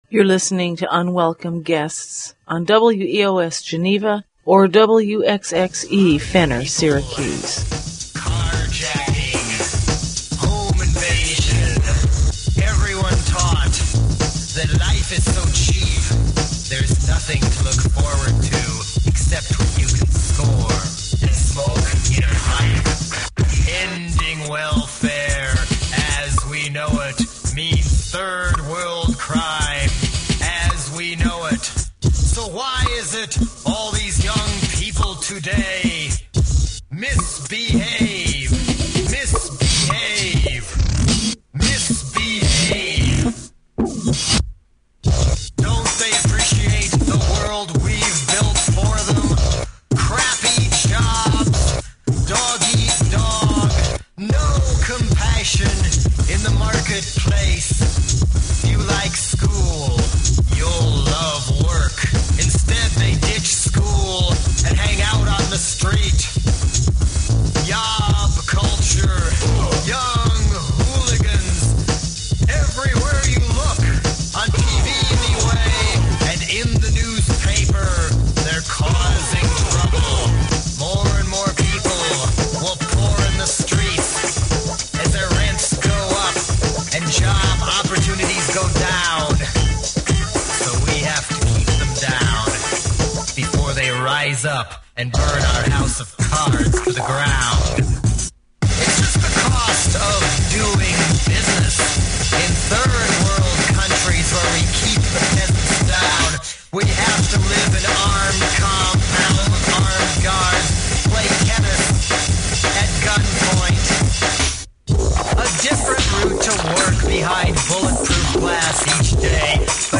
In the second hour, some examples of class war mechanisms by the corporate state against the citizen: excerpts from an article on Operation Garden Plot, a plan to use the military to crack down on dissent in America, e-watch, corporations track critics on the web, and the Battle of Wellfleet, a small town is forced to accept cellular transmissions towers after courts rule their ordinances violate "corporate civil rights." We conclude with a Michael Parenti speech, "Who Governs?", a detailed analysis of how elites dominate government policy and offices.